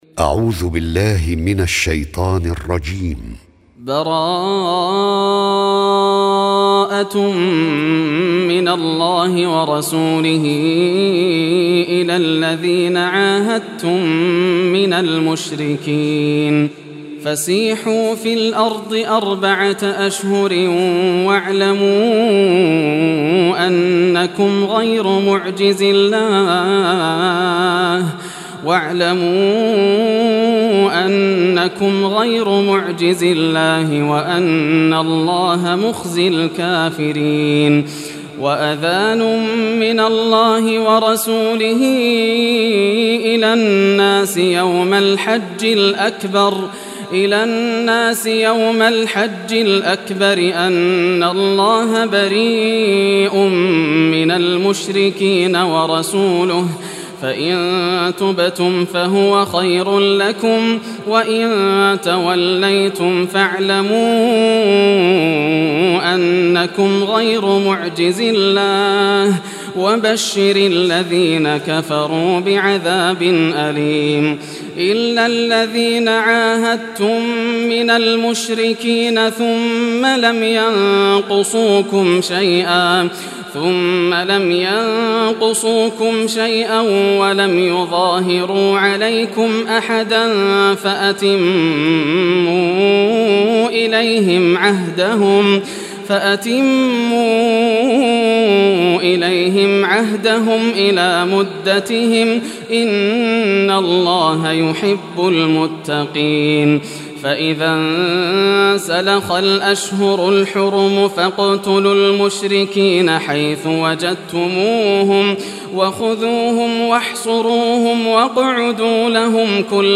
Surah At-Tawbah Recitation by Sheikh Yasser Dosari
Surah At-Tawbah, listen or play online mp3 tilawat / recitation in Arabic in the beautiful voice of Sheikh Yasser al Dosari.